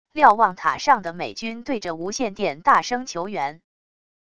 瞭望塔上的美军对着无线电大声求援wav音频